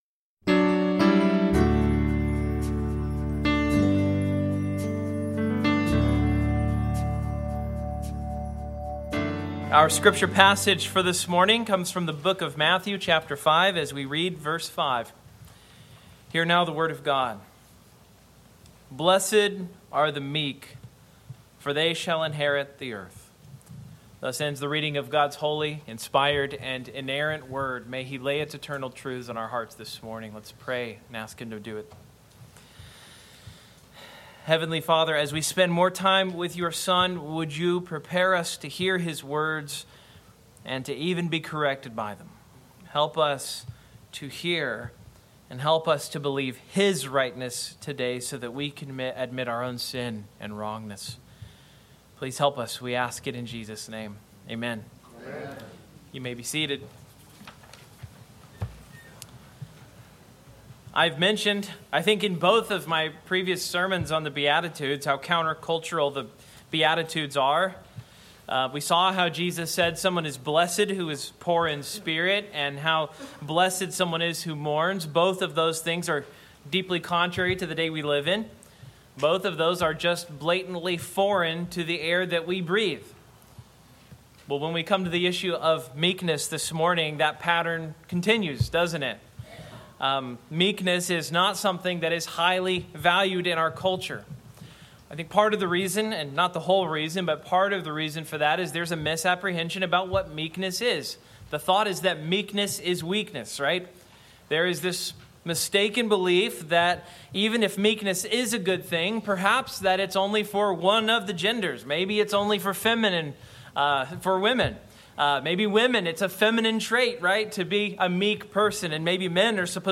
Matthew 5:5 Service Type: Morning « The Beatitudes